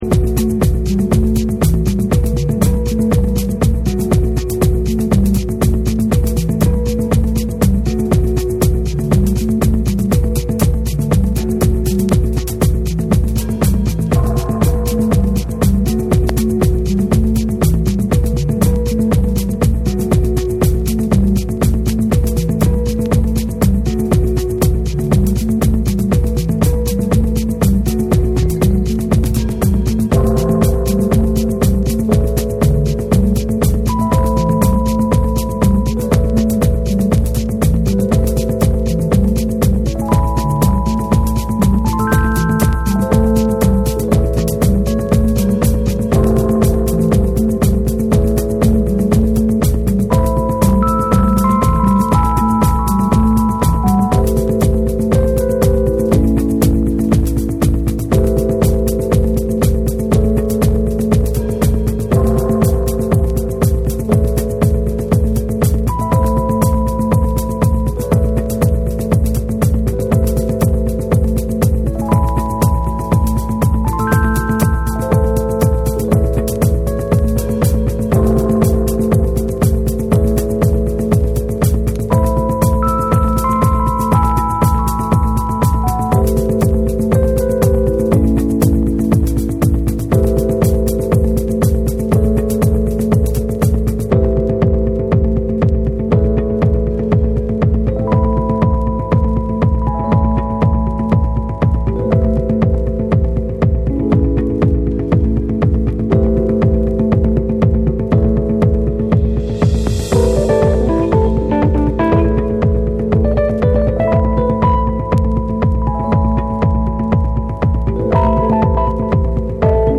流麗に奏でるフェンダーローズのメロディーが4/4トラックと相まるダンス・トラック
JAPANESE / BREAKBEATS